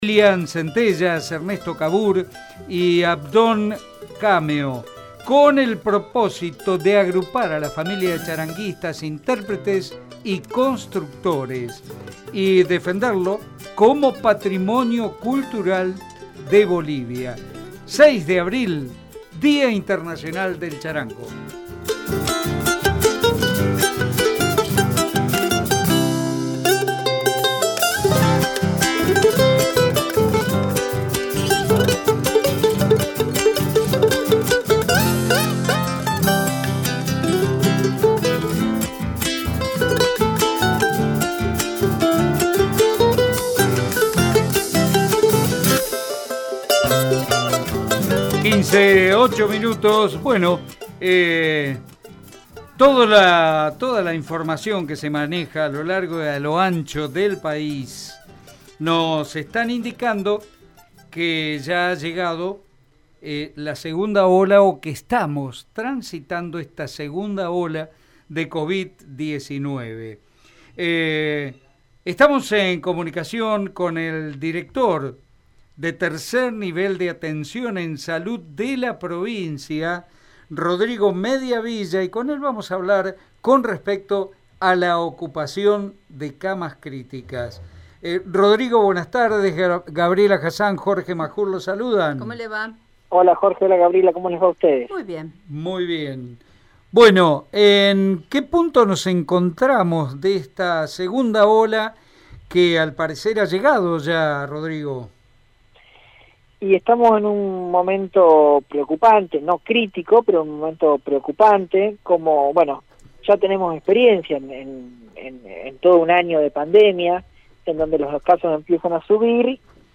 En dialogo con Radio EME, el director tercer nivel de Salud de Santa Fe, Rodrigo Mediavilla, aseguró que no podrán ampliar más el sistema de salud de la provincia.